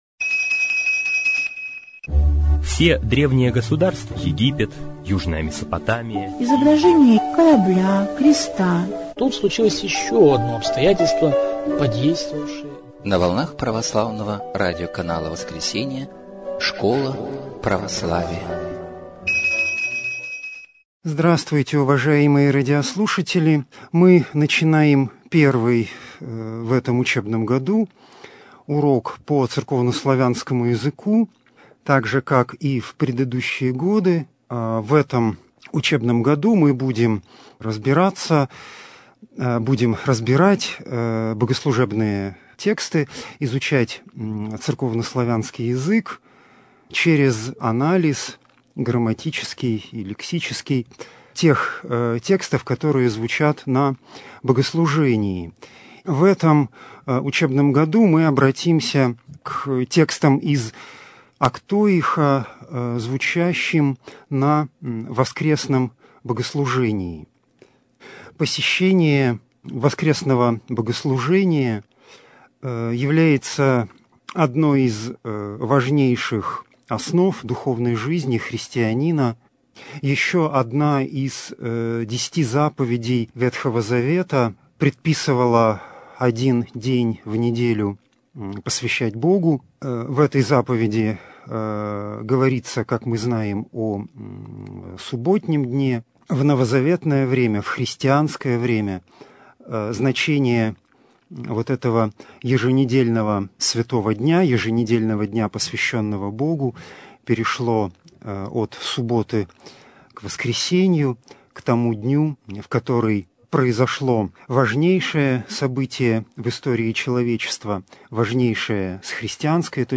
Церковно-славянский язык 2021-22. Урок 2